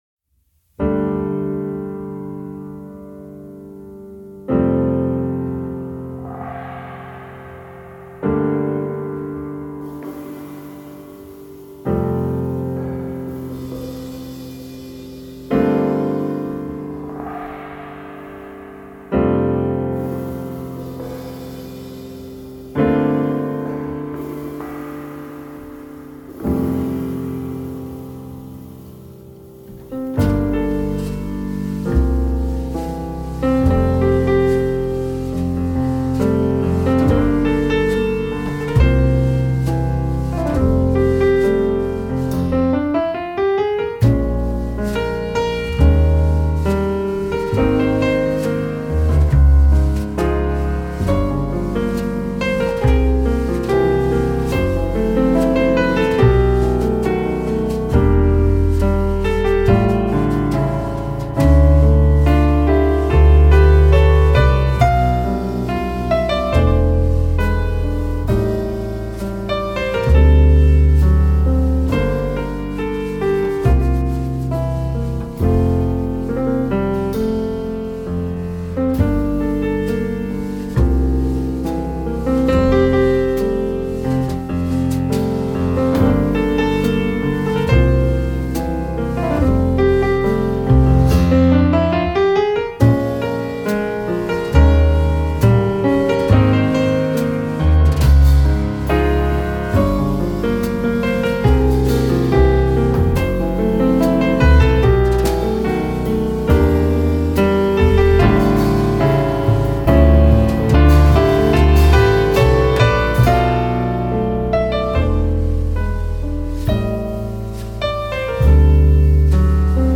メロウな極上バラードが印象的。
piano
drums
double bass